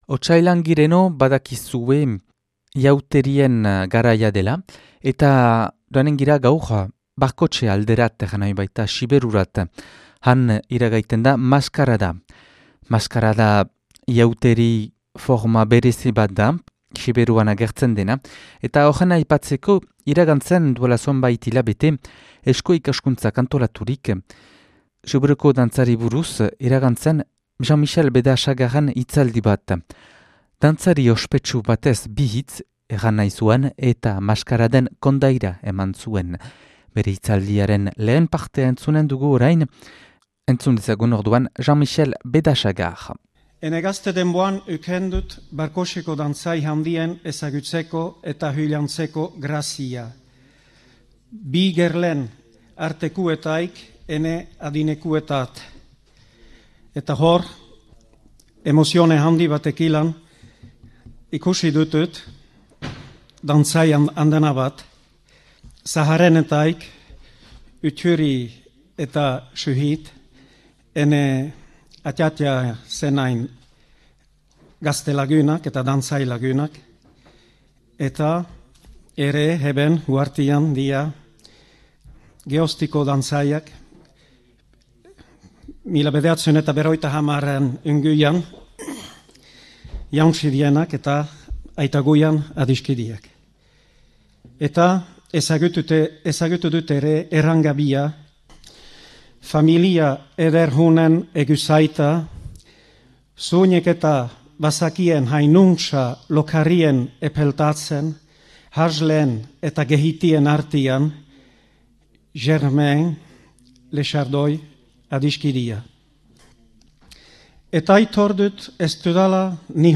mintzaldia
(2006. Azaroaren 11an grabatua Barkoxen).